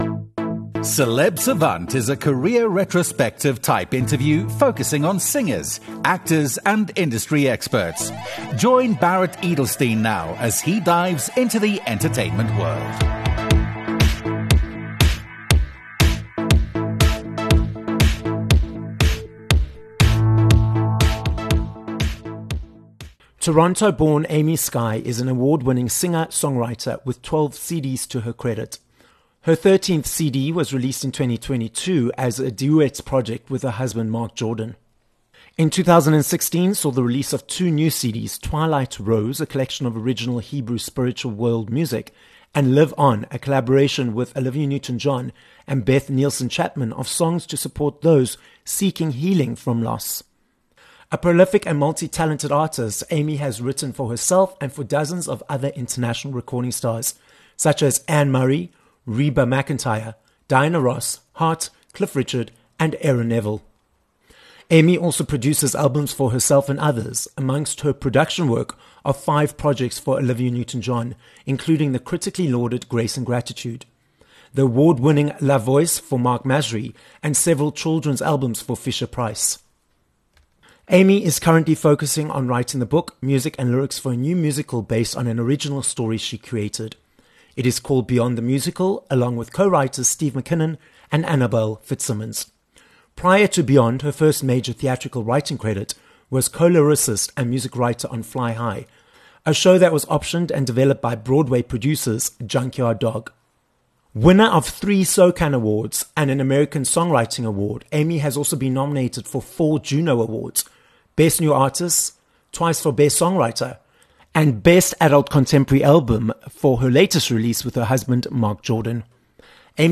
7 May Interview with Amy Sky
Having written songs for Diana Ross, Heart, and Reba McEntire... and produced albums for Olivia Newton-John amongst many others, we are joined on this episode of Celeb Savant by Canadian singer and songwriter, Amy Sky. Amy tells us the difference between creating music for herself compared to creating for others, and her multi-decade journey of being a successful award-winning singer, songwriter and producer.